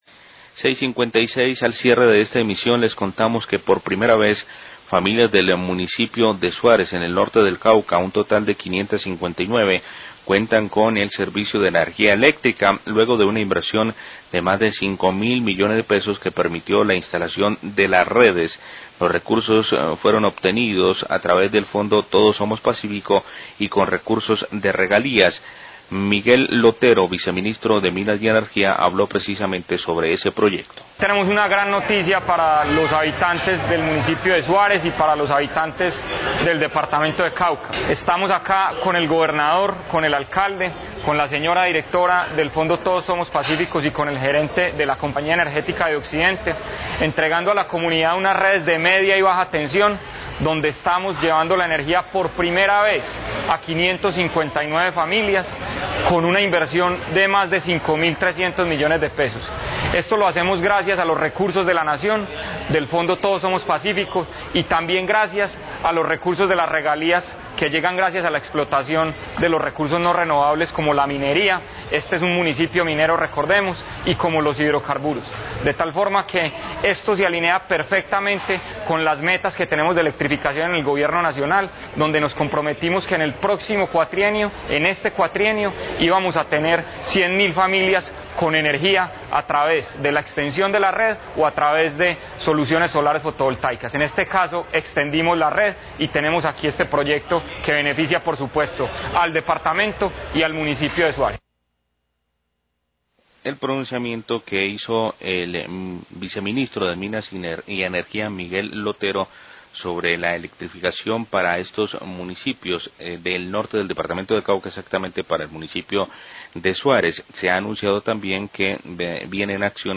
Viceministro de Energía habla de la electrificación zonas rurales de Suárez
Radio
El Viceministro de Minas y Energía acompañó Gobernador del Cauca, a la Directora del Fondo Todos Somos Pazcífico, al Alcalde de Suárez y a Directivos de la Compañía Energética y comunidad entregando el servicio de energía eléctrica en Suárez, se llegó a 559 hogares con recursos del fondo de Todos Somos Pazcífco y de Regalías. Declaraciones del funcionario.